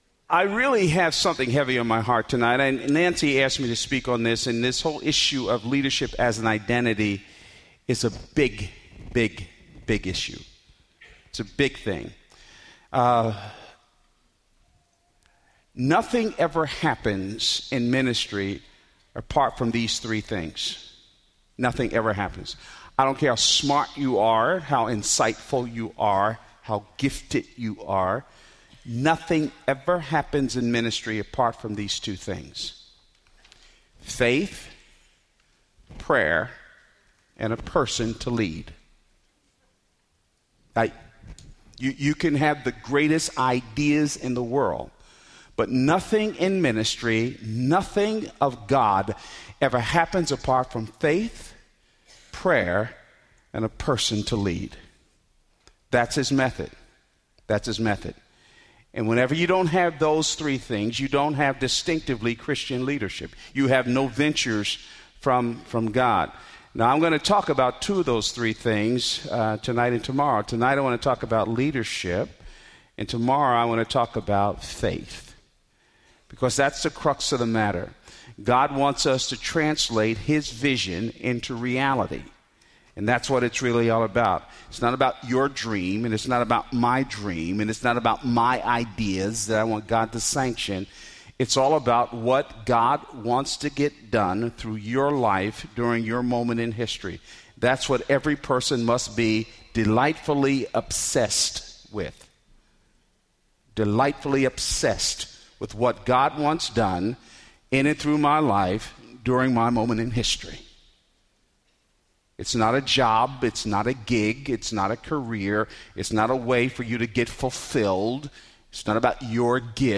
Four Traits of Leaders | Revive '11 | Events | Revive Our Hearts